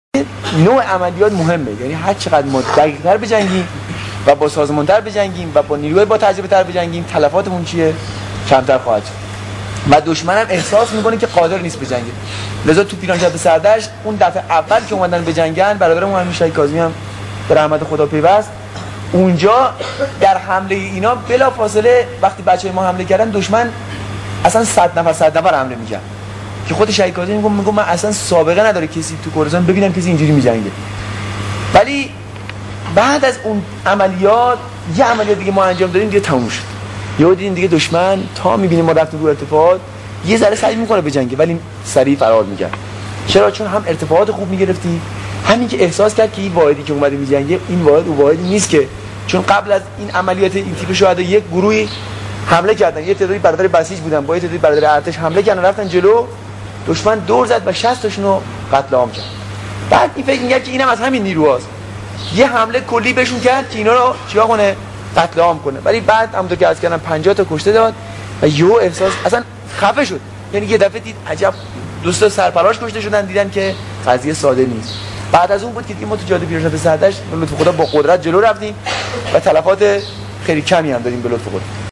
صوت سخن شهید بروجردی در جمع رزمندگان